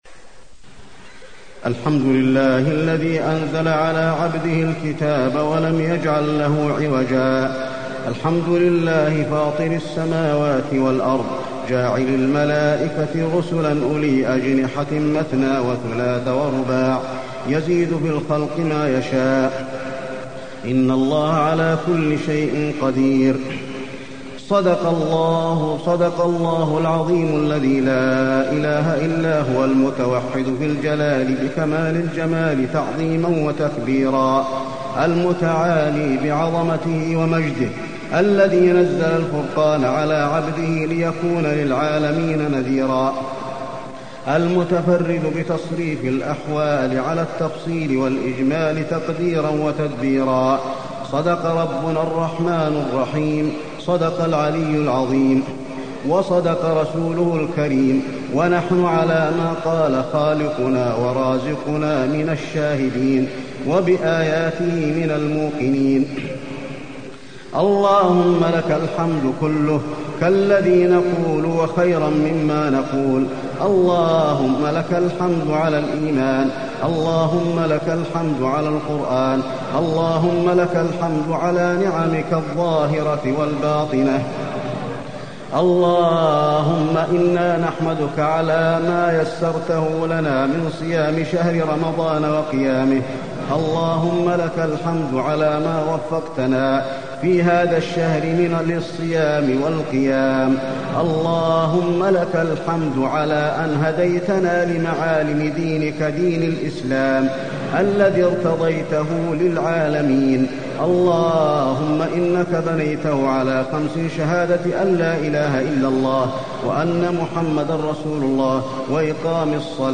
دعاء ختم القرآن
المكان: المسجد النبوي دعاء ختم القرآن